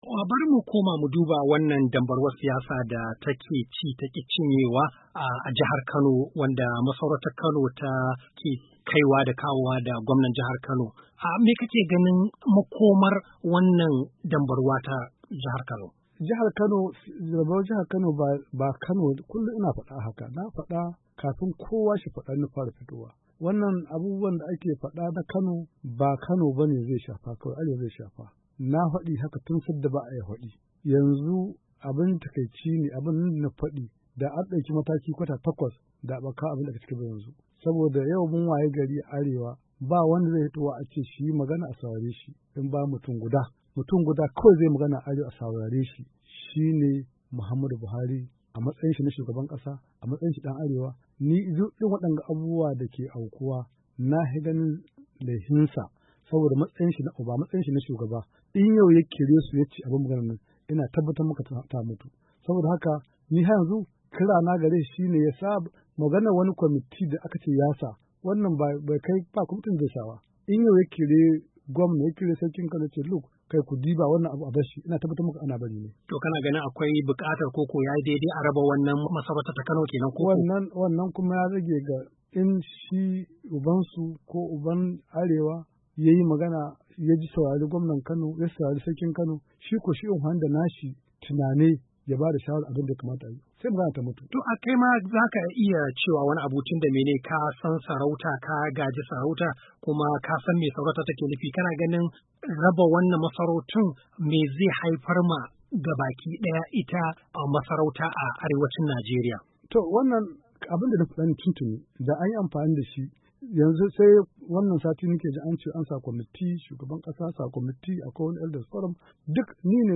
Bafarawa ya yi wadannan kalamai ne, yayin wata ziyara da ya kai ofishin Sashen Hausa na Muryar Amurka a Washington.
Saurari hirarsu cikin sauti.